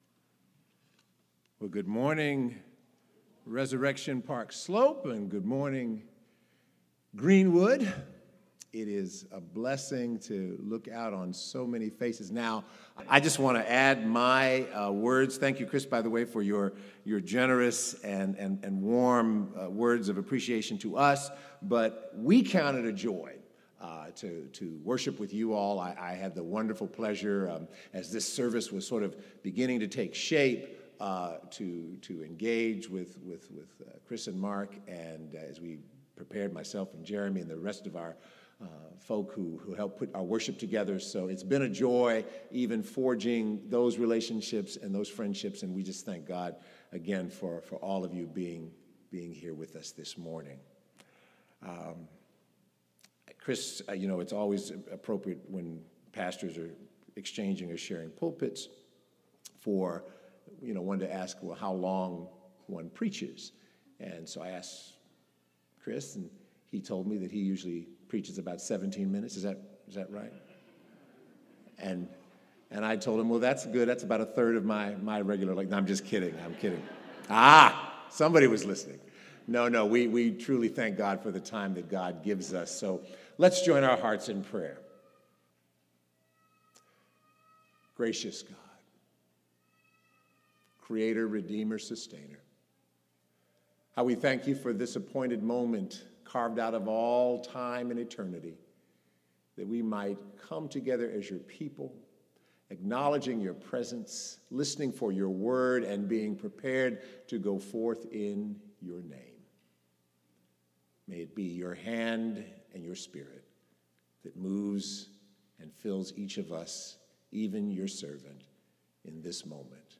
Celebrating joint worship service with our friends from Resurrection Park Slope